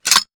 weapon_foley_drop_21.wav